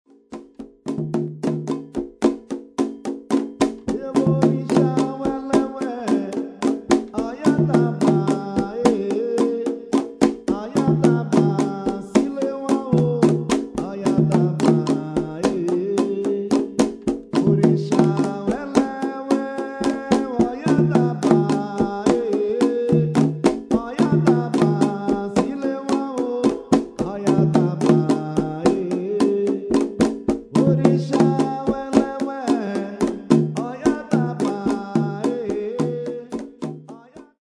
Traditional Orixa Songs and rhythms